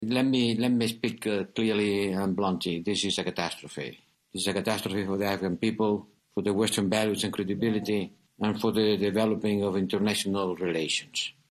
În discursul său pentru Parlamentul European, Josep Borrell a spus că aproximativ 100 de angajați ai Uniunii Europene și 400 de afgani care lucrează cu UE și familiile lor au fost evacuați, dar că încă 300 de afgani încearcă să plece.
19aug-14-Borrell-este-o-catastrofa.mp3